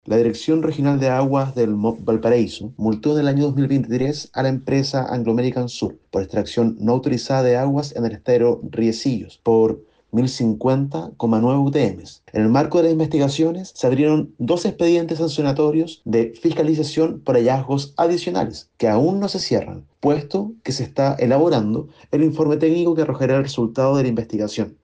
El director regional de Aguas del Ministerio de Obras Públicas de Valparaíso, Camilo Mansilla, ahondó en la investigación que se está llevando a cabo, sin revelar el contenido que solo se sabrá una vez se cierren ambos expedientes.